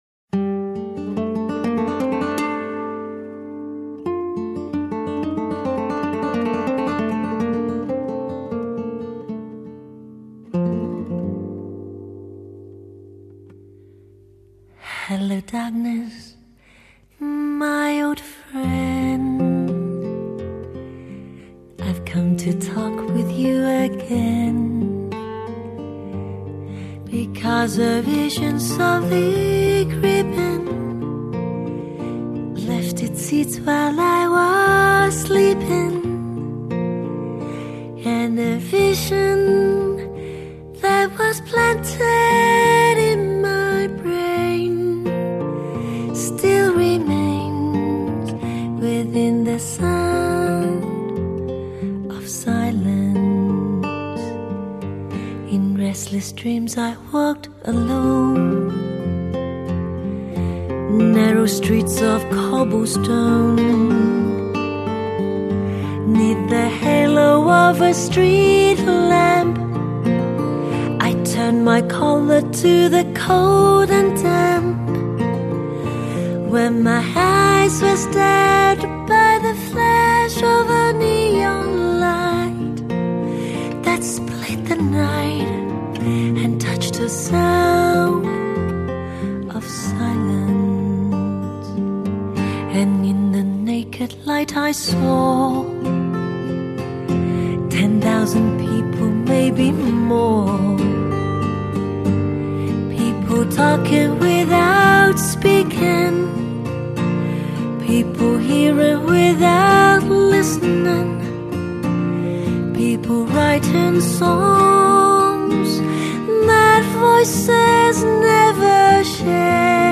==献上HIFI天后的歌声  祝大家2011新年快乐==
特别的时代，特别的音乐，轻松Acoustic Pop、民谣、Bossa Nova，每首歌令你百感交集，经典好歌！